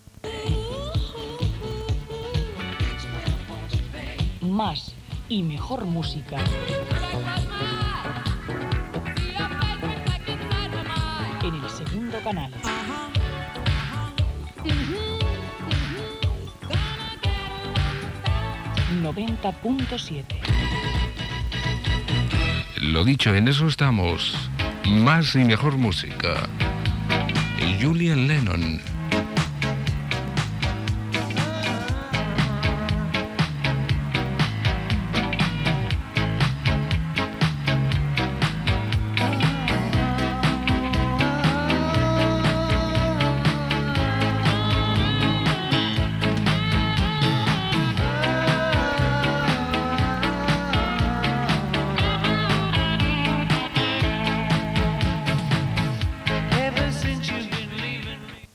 Indicatiu i tema musical. Gènere radiofònic Musical